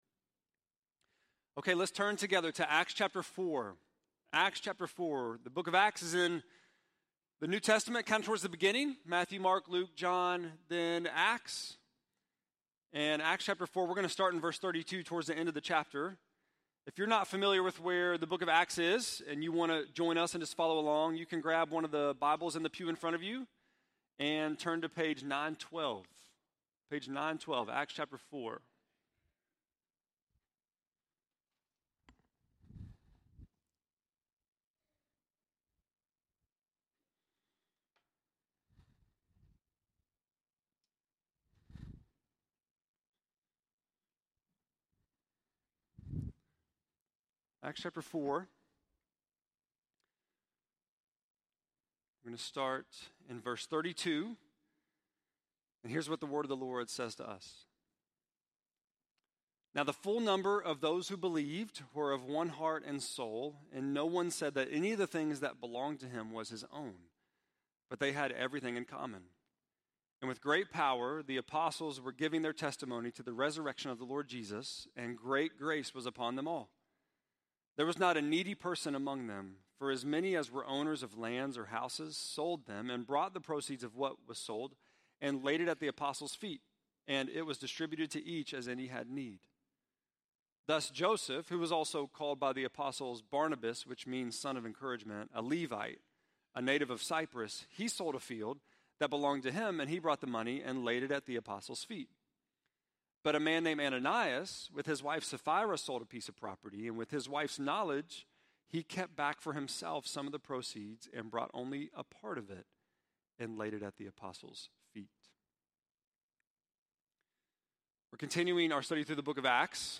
6.30-sermon.mp3